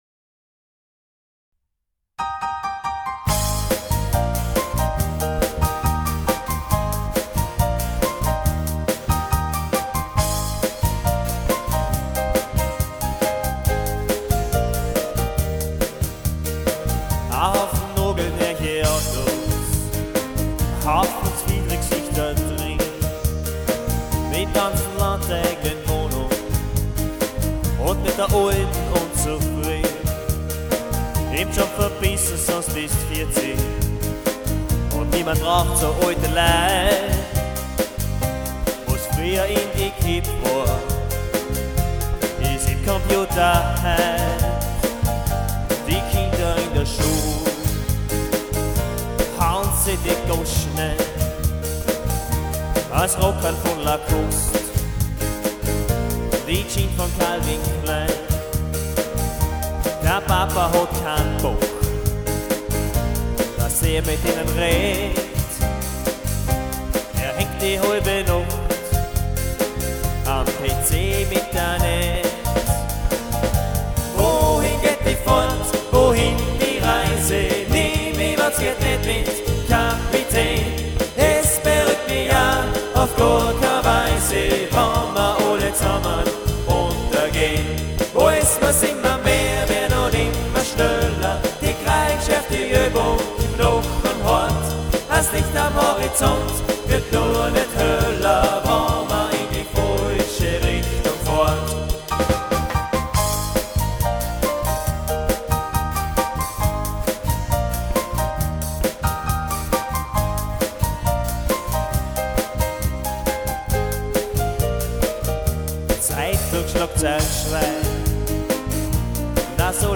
4 Musiker